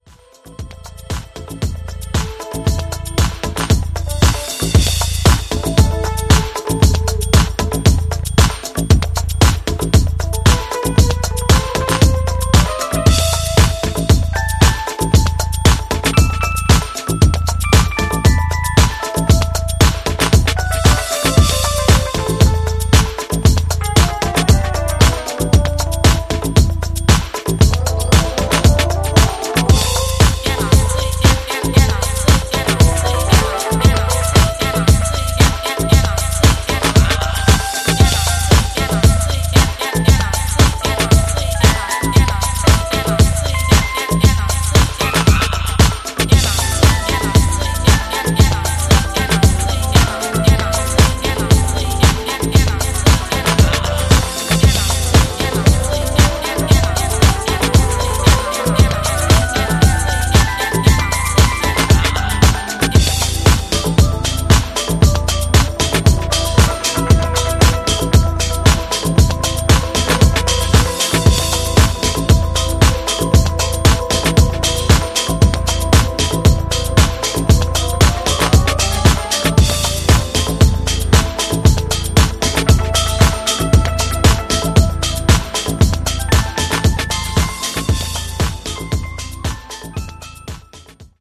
エレピのメロディーやヴォイス・リフを配しながら115BPMでディープに引き込んでいくそちらももちろん良し。
ジャンル(スタイル) DEEP HOUSE / HOUSE